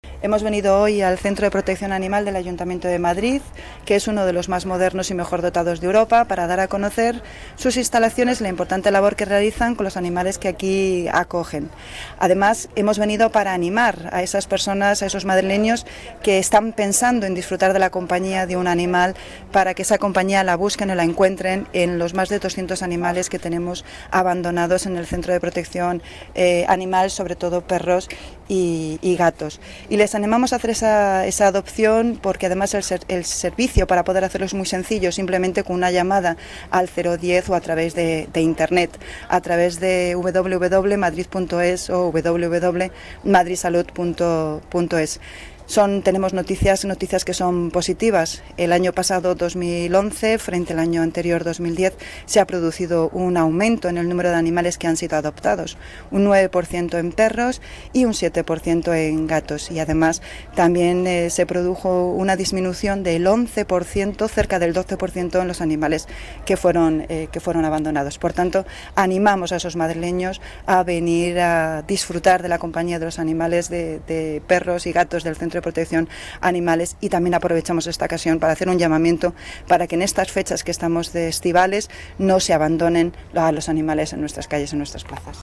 Nueva ventana:Declaraciones concejala delegada Seguridad y Emergencias, Fátima Núñez: datos Centro Protección Animal